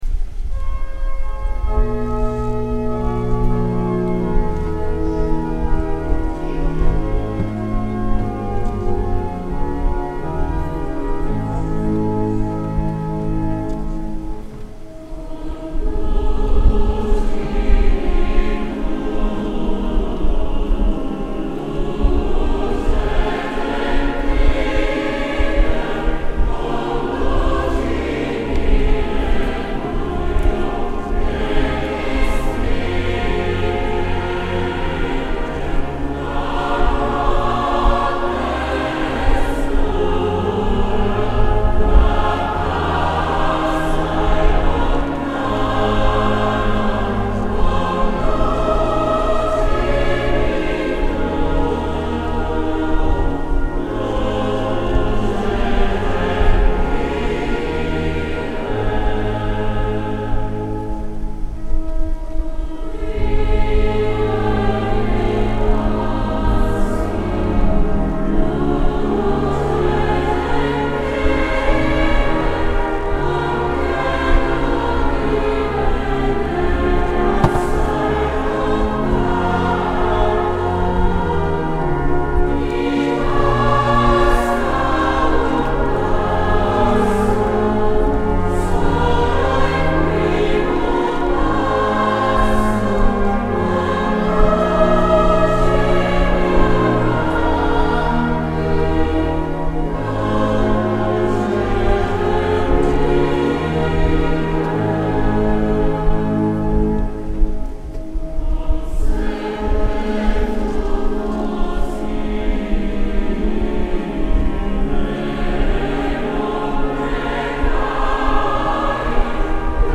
Gallery >> Audio >> Audio2022 >> Ordinazione Arcivescovo Isacchi >> 19-LuceGentile OrdinazArcivescovo 31Lug22